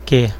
Ääntäminen
Ääntäminen BR Haettu sana löytyi näillä lähdekielillä: portugali Käännöksiä ei löytynyt valitulle kohdekielelle.